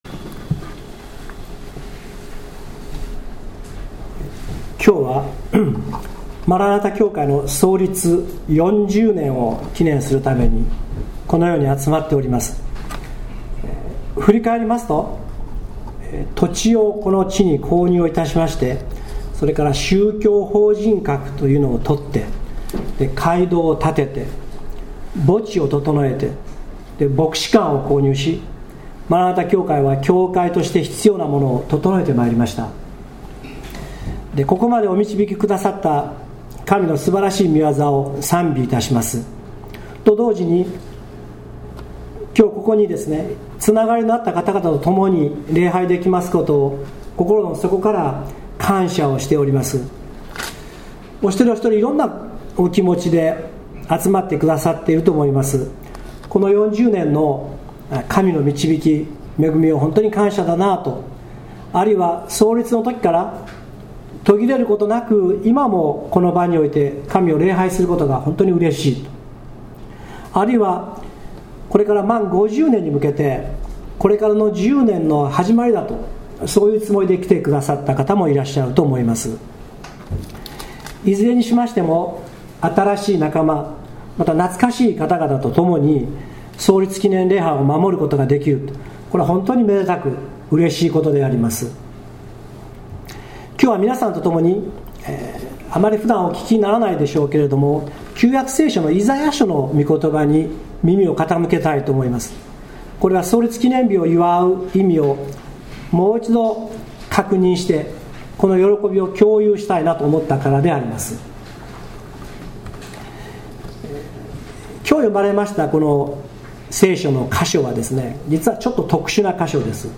マラナ・タ教会創立４０周年記念礼拝
創立４０周年を迎えますにあたり、９月１６日(日)、通常の礼拝とは別に午後３時より『４０周年記念礼拝』を献げました。